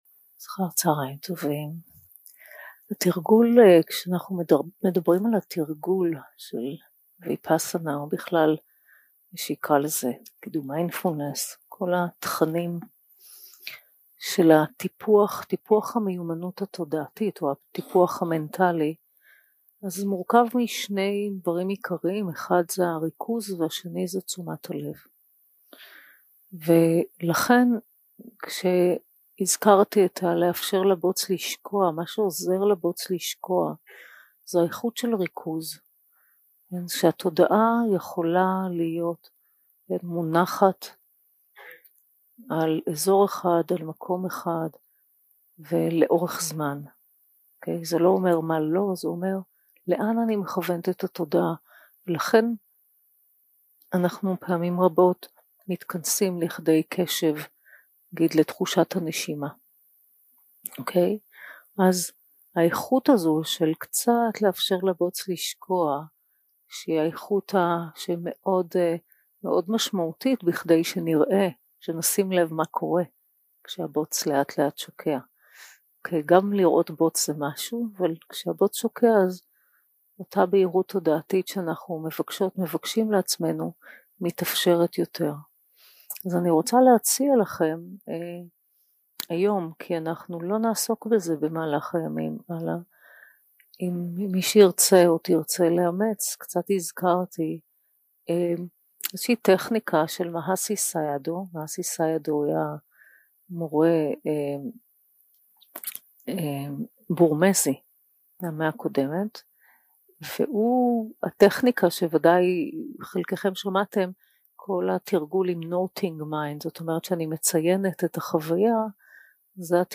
יום 2 – הקלטה 4 – צוהריים – מדיטציה מונחית – טכניקות לריכוז התודעה
יום 2 – הקלטה 4 – צוהריים – מדיטציה מונחית – טכניקות לריכוז התודעה 0:00 0:00 סוג ההקלטה: Dharma type: Guided meditation שפת ההקלטה: Dharma talk language: Hebrew